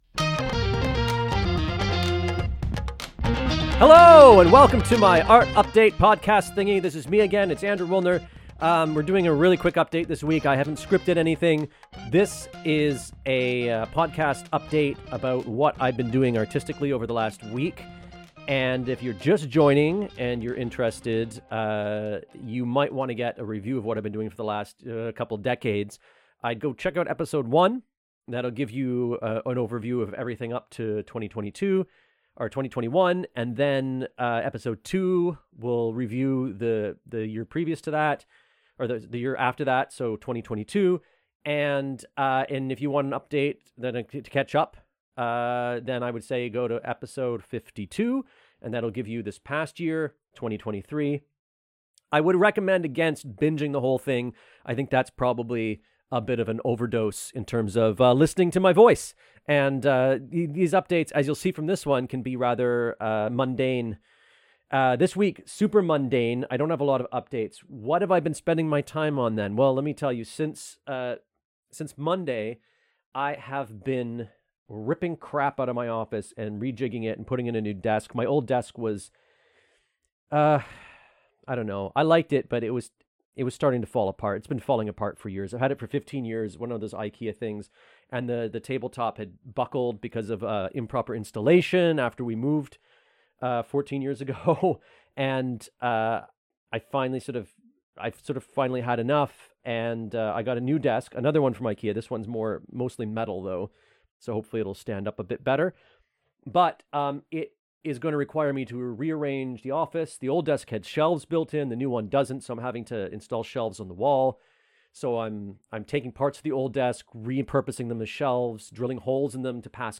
Non-scripted quickie to hit the deadline this week. I’ve been re-arranging my office as I replace my old falling-apart desk, and that’s occupied nearly all of my attention this week.